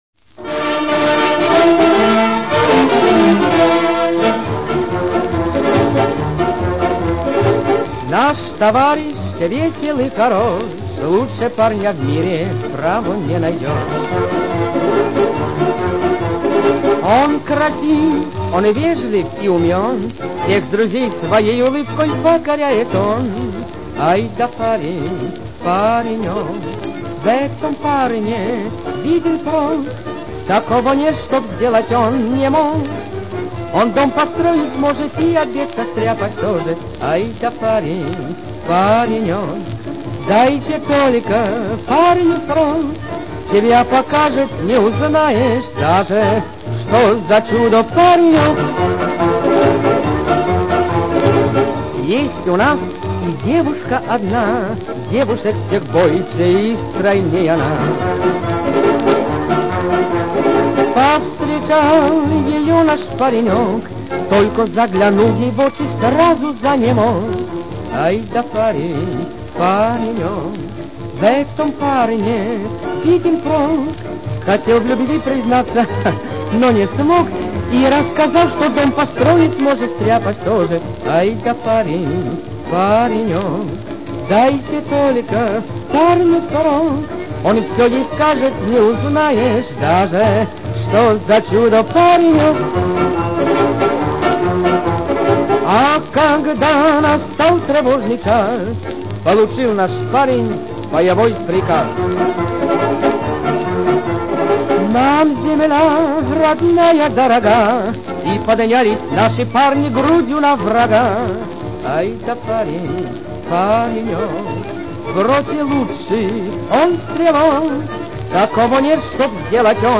Вариантов этой песни множество, этот создан во время войны.